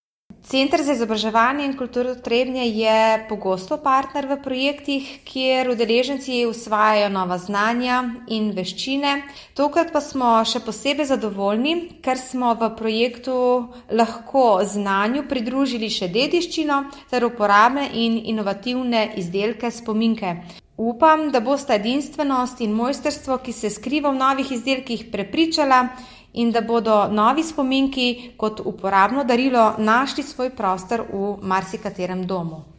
tonska izjava